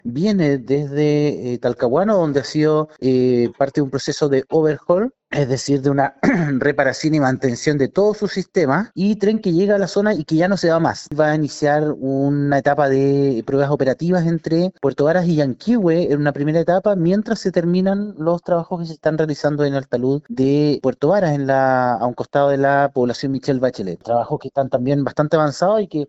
Al respecto, el Seremi de Transporte, Pablo Joost indicó que para iniciar su recorrido completo, desde Puerto Montt a la comuna lacustre, deberán terminarse las obras del talud en el sector Michelle Bachelet en Puerto Varas.